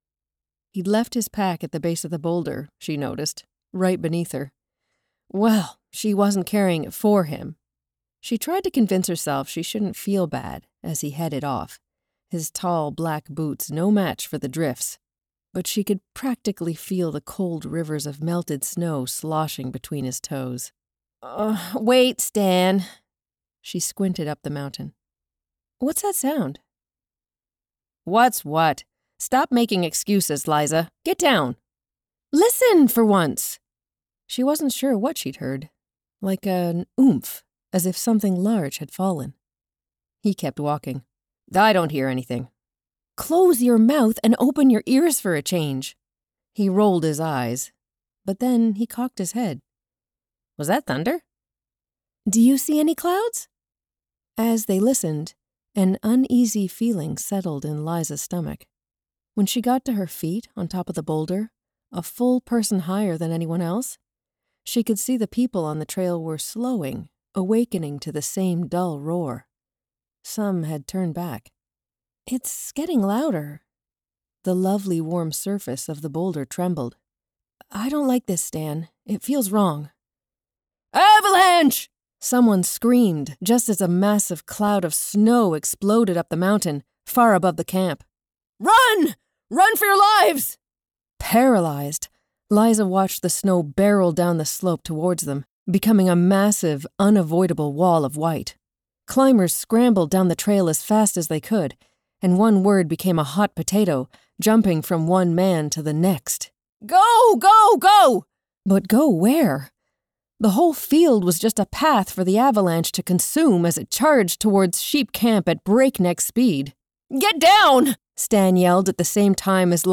Warm, flexible, seasoned, and authentic.
Audiobook Fiction Characters (Canadian)